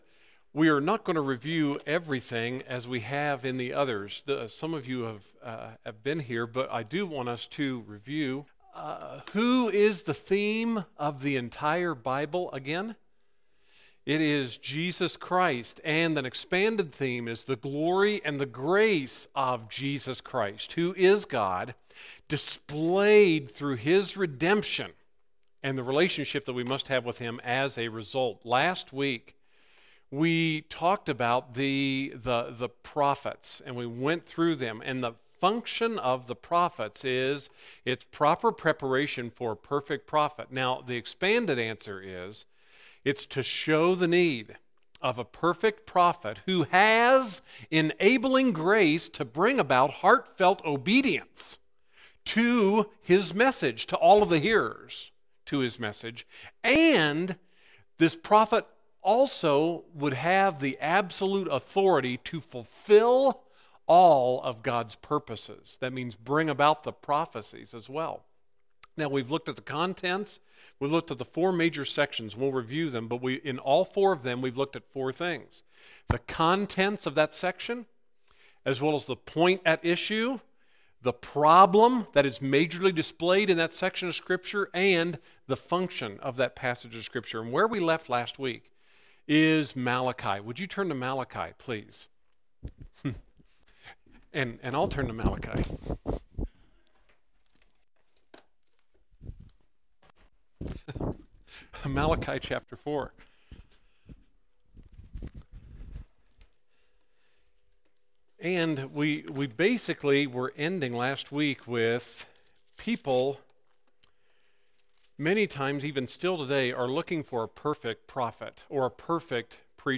Sunday School…